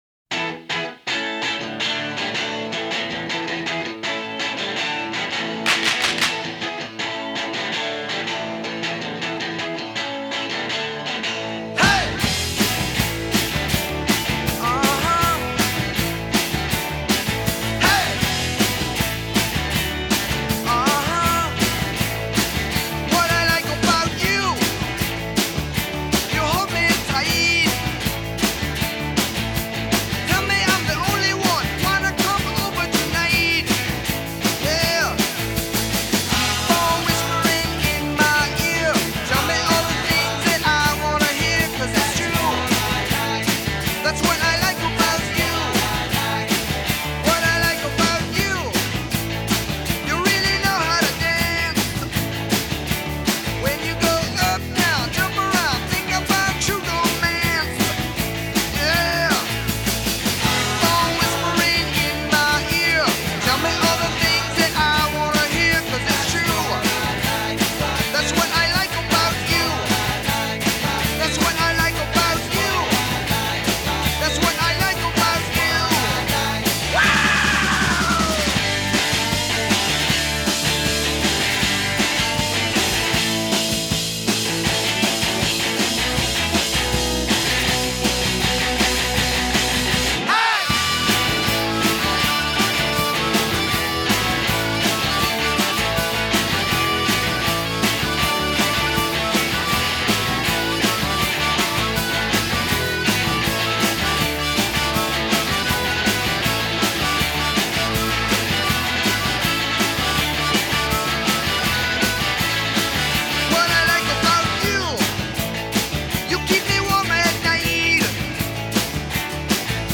Категория: Хиты 80-х